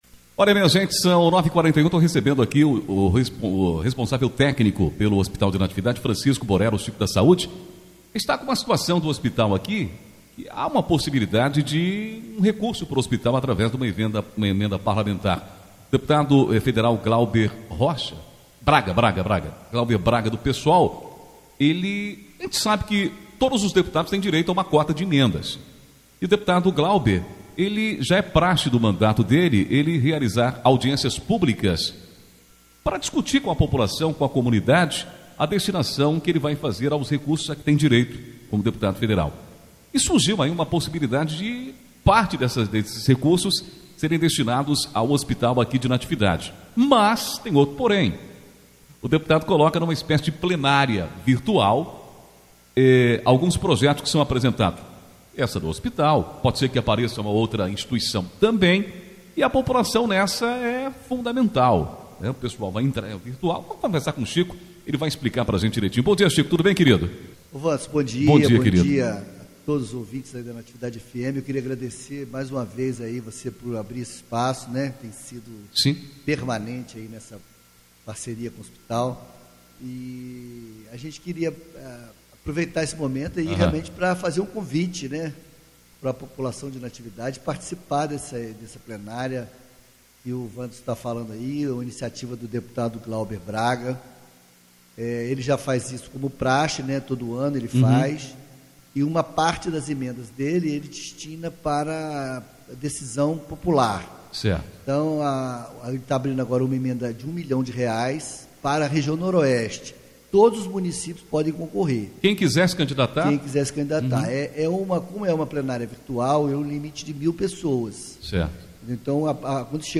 falou à Rádio Natividade